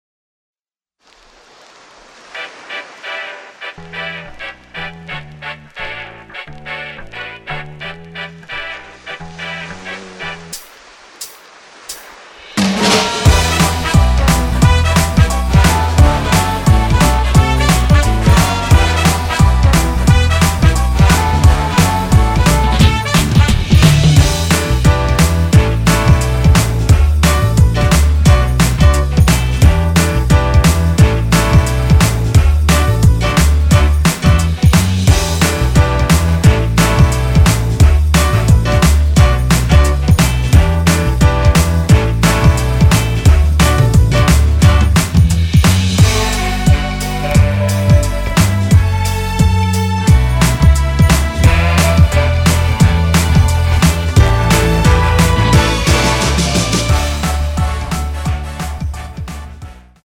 [공식 음원 MR]
앞부분30초, 뒷부분30초씩 편집해서 올려 드리고 있습니다.
중간에 음이 끈어지고 다시 나오는 이유는